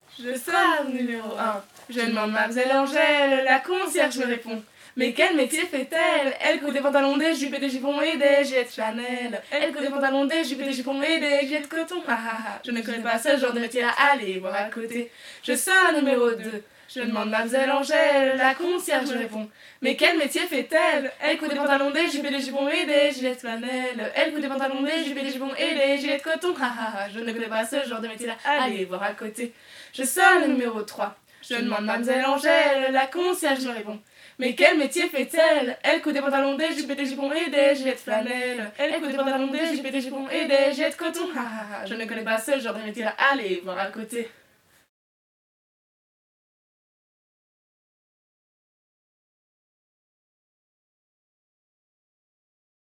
Genre : chant
Type : chant de mouvement de jeunesse
Interprète(s) : Les Scouts de Sibret
Lieu d'enregistrement : Sibret